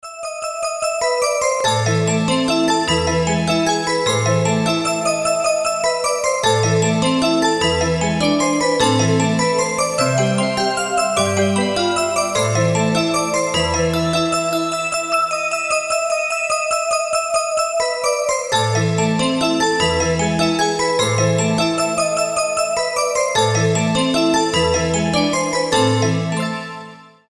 • Качество: 320, Stereo
мелодичные
без слов
колокольчики
звонкие
Более современное исполнение легендарной